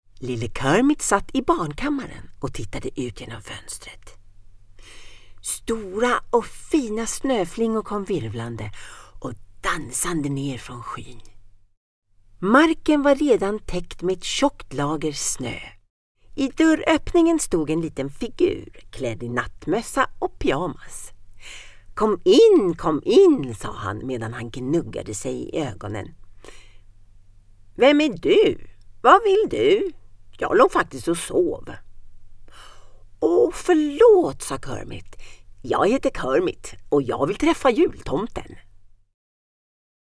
Deep, Accessible, Mature, Warm